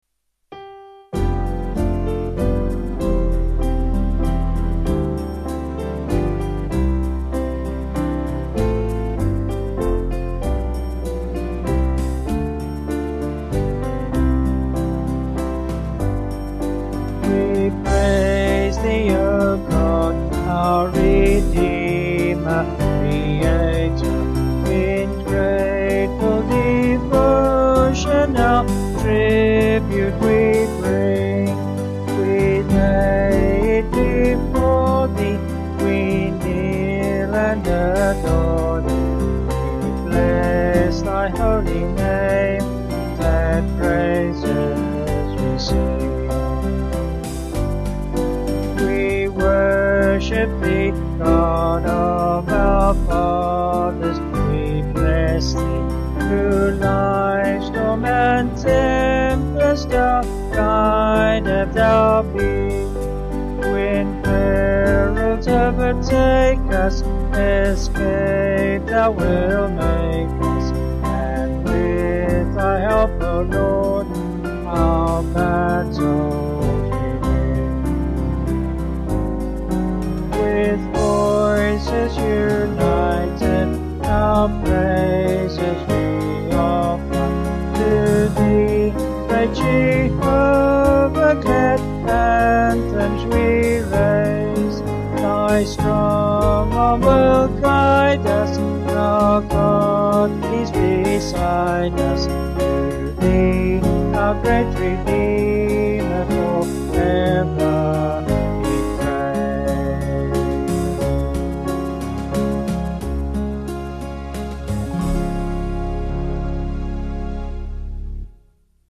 Vocals and Band   263.6kb Sung Lyrics